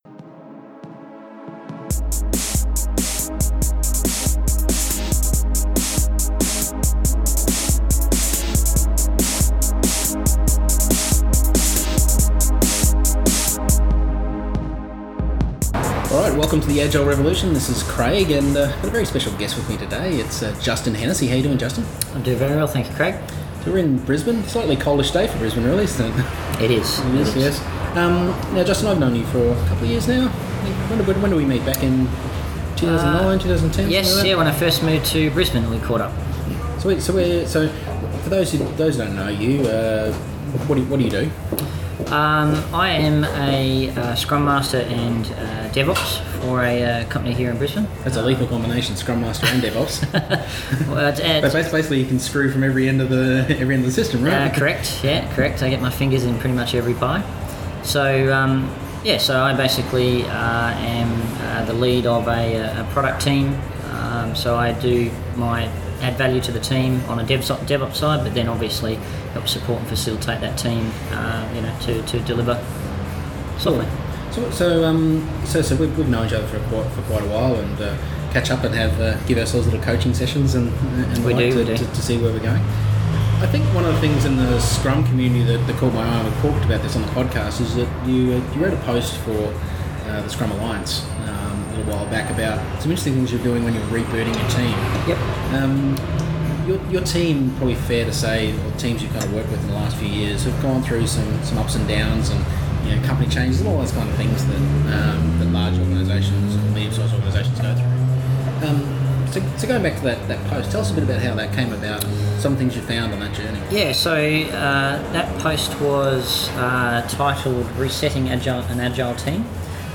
Sitting in a sometimes noisy coffee shop on a unusually cold Brisbane day